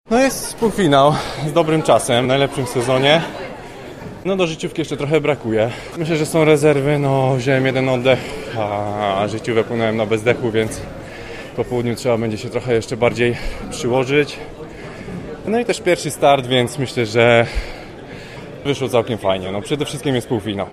Był 9. w całej stawce i dostał się do popołudniowego półfinału. Jak mówi zawodnik AZS UMCS Lublin, czuje, że ma jeszcze rezerwy na popołudniowy start: